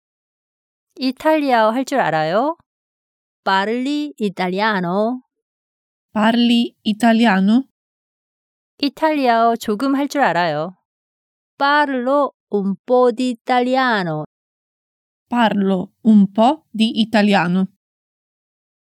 ㅣ빠를리 이딸리아아노ㅣ
ㅣ빠를로 운뽀디 이딸리아아노ㅣ